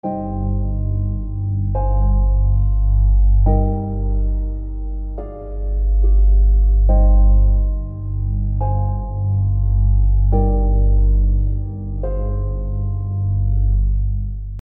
Creating a sense of unchanging sorrow: A sustained tonic or dominant pedal.
Pedal Tones